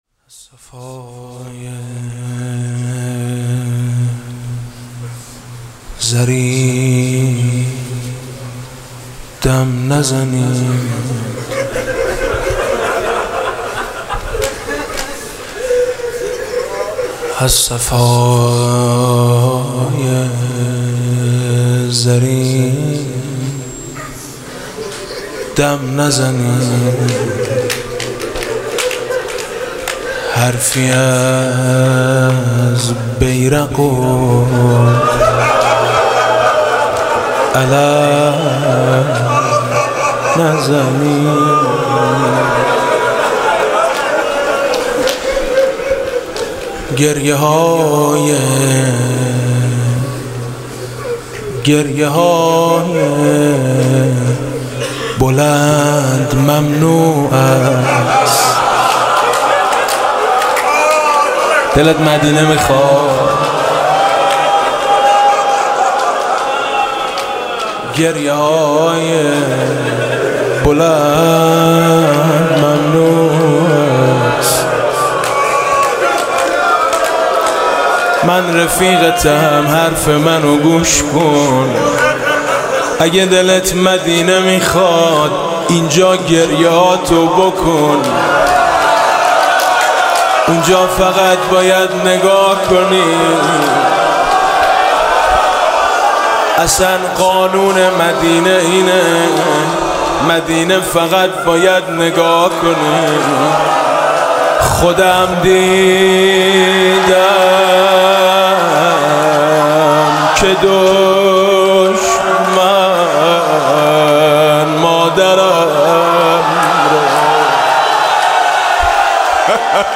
«ویژه مناسبت تخریب بقیع» روضه: هشت شوال آسمان لرزید